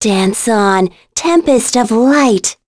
Talisha-Vox_Skill7.wav